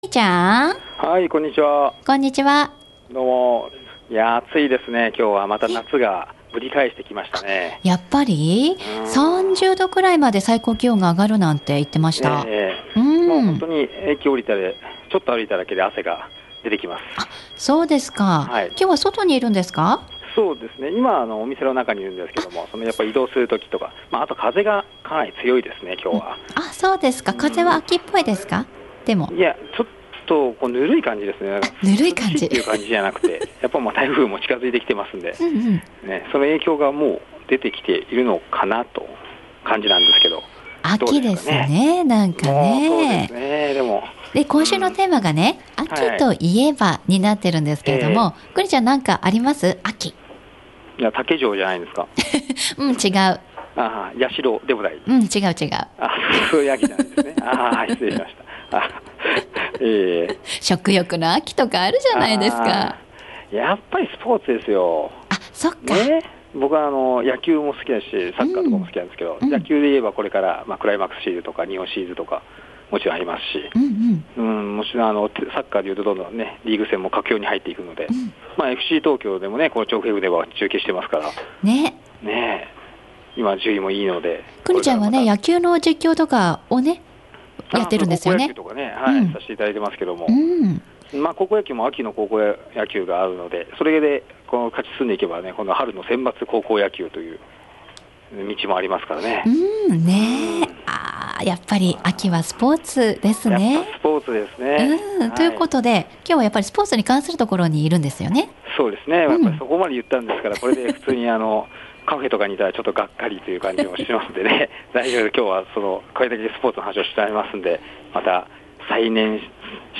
序盤にお聞き苦しい箇所がございました。申し訳ありません。